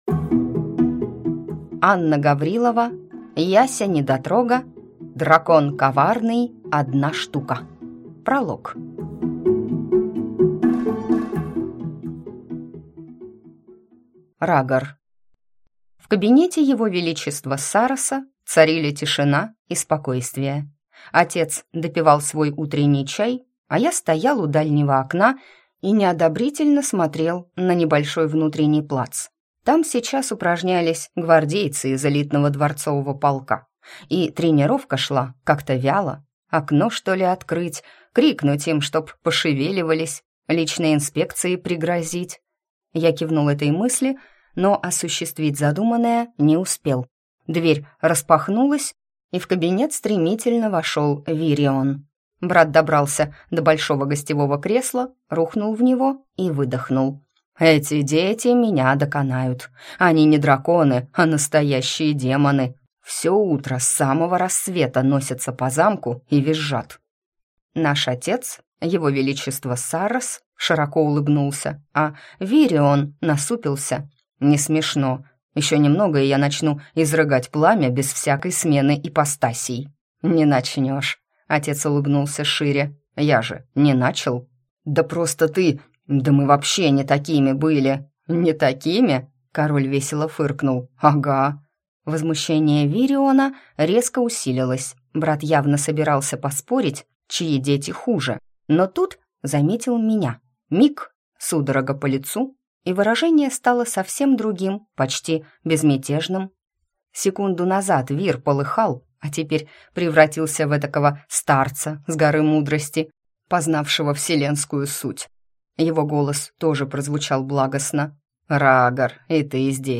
Аудиокнига Дракон коварный, одна штука | Библиотека аудиокниг